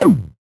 Index of /musicradar/8-bit-bonanza-samples/VocoBit Hits
CS_VocoBitB_Hit-16.wav